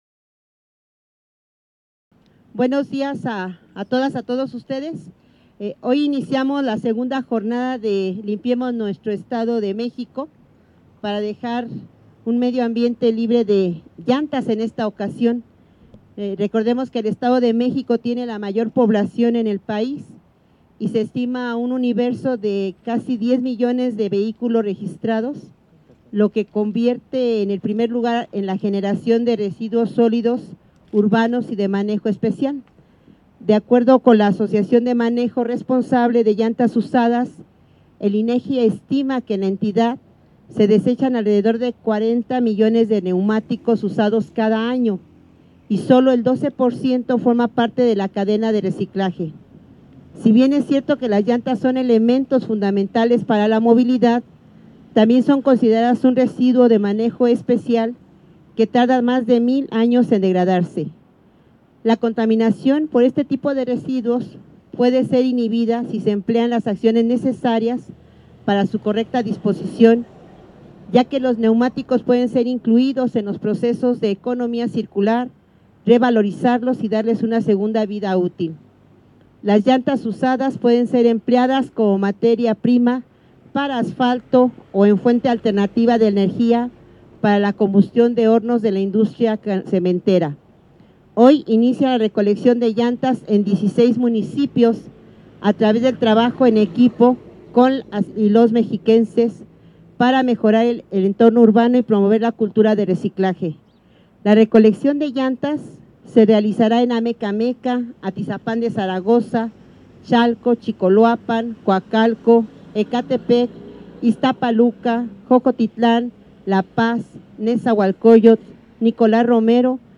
Audio-Completo-DGA_Segunda-Jornada-Limpiemos-Nuestro-EdoMex.mp3